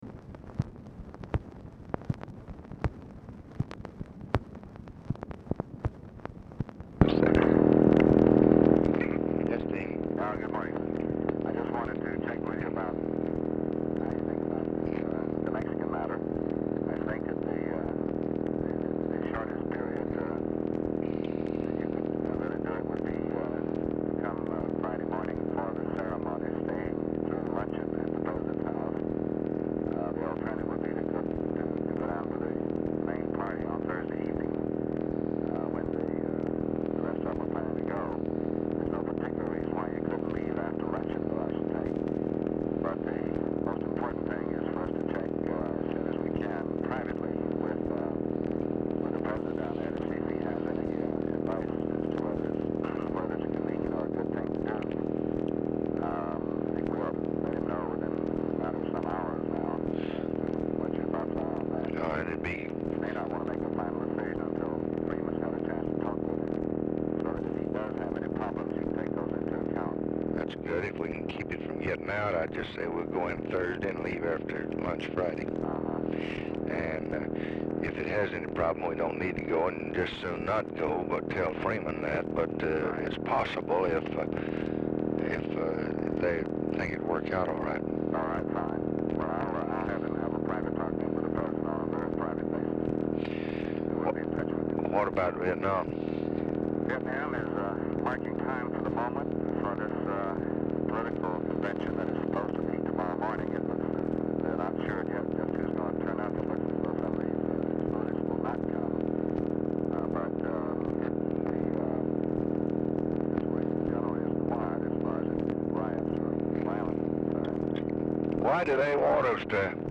Telephone conversation # 10032, sound recording, LBJ and DEAN RUSK, 4/11/1966, 10:50AM | Discover LBJ
POOR SOUND QUALITY; BACKGROUND STATIC
Format Dictation belt
Location Of Speaker 1 LBJ Ranch, near Stonewall, Texas